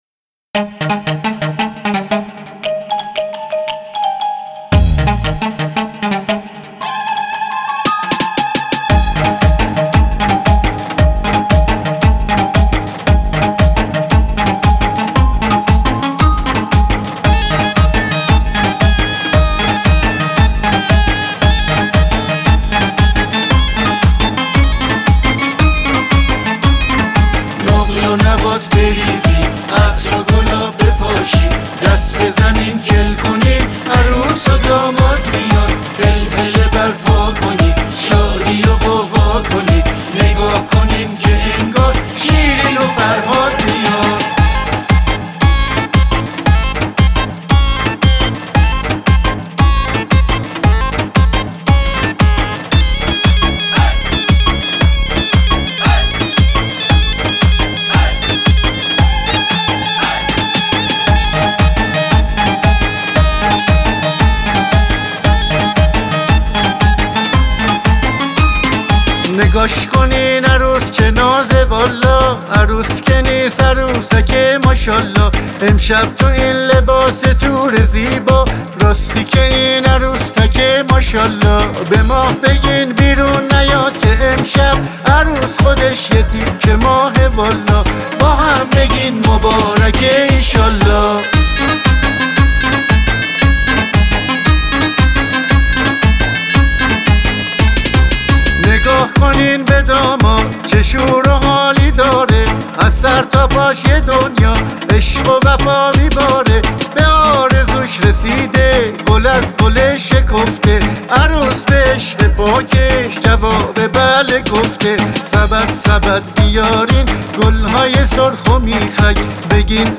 آهنگ ریتمیک و شاد عروس و داماد